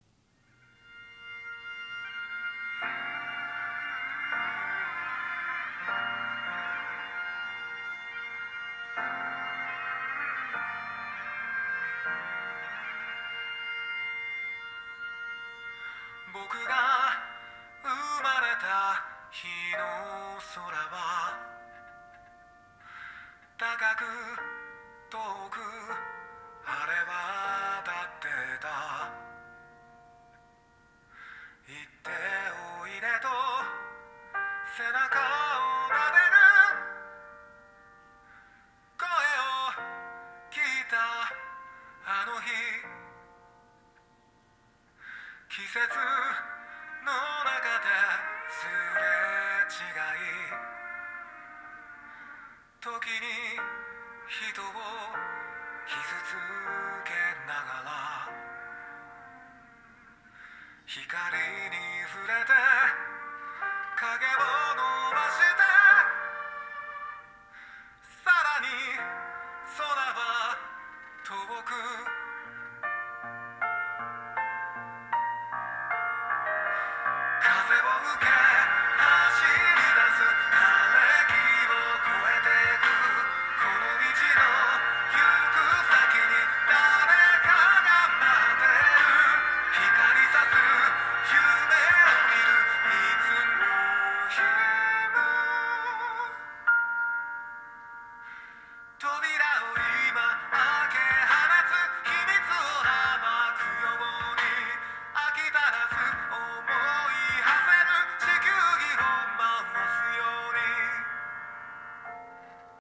参考までに、ヘッドホンから聞こえるサウンドを録音したデータを掲載する。
▼ヘッドホンにマイクを近接させて録音。
※あくまで低音・高音の強さをなんとなく分かってもらうためのものなので要注意。実際には、よりクリアで響きの良いサウンドが耳に届けられる。